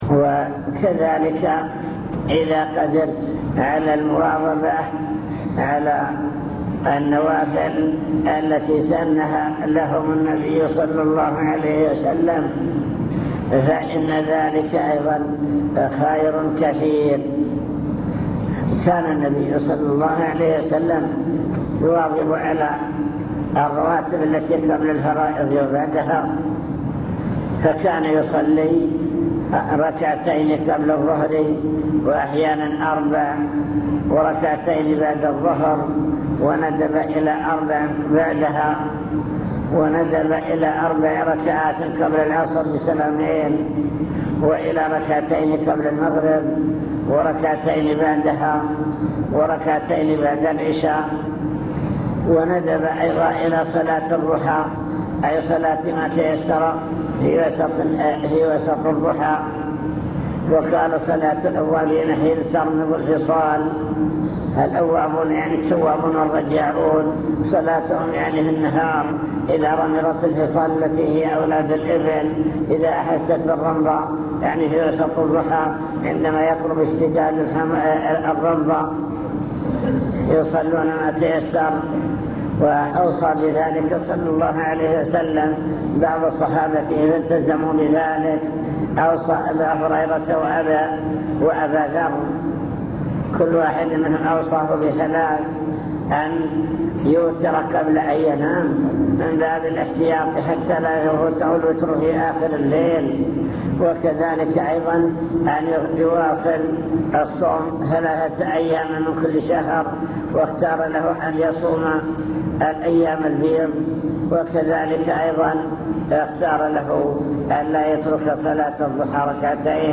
المكتبة الصوتية  تسجيلات - محاضرات ودروس  محاضرة في النصرية أحوال سلف الأمة في العبادة